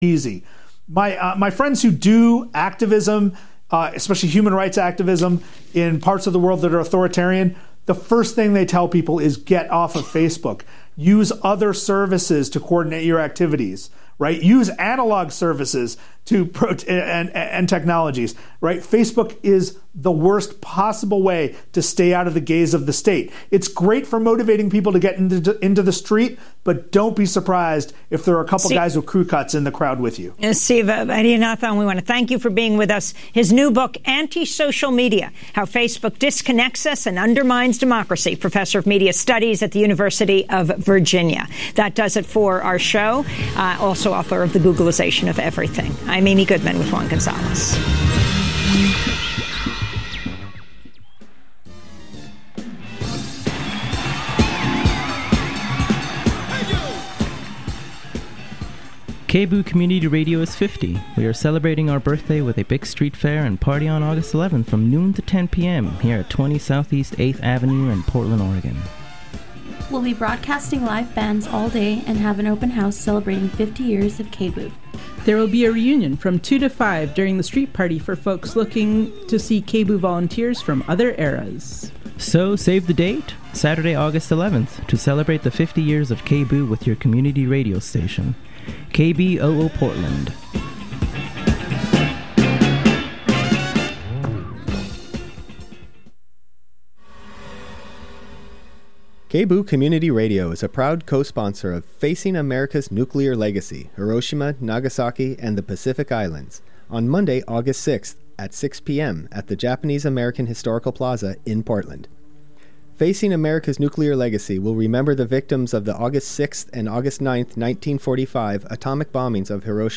Wednesday Talk Radio